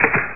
dropgun.mp3